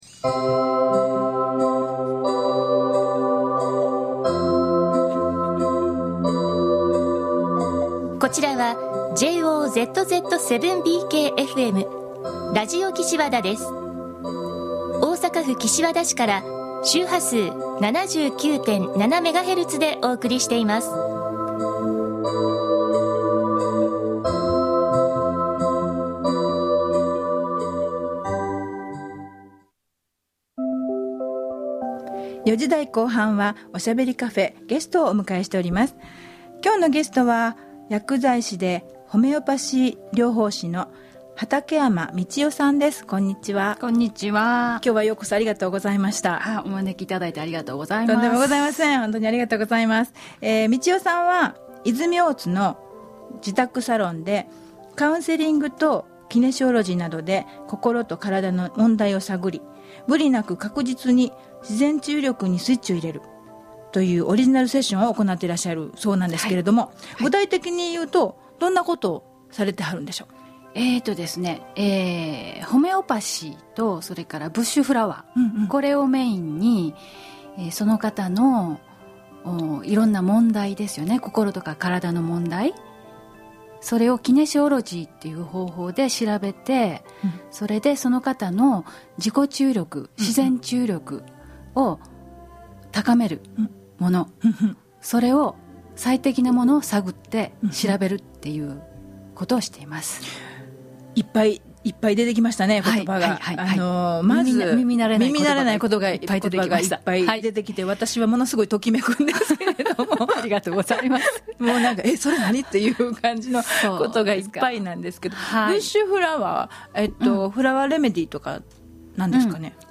ラジオでゲストトークしました（2013.3月）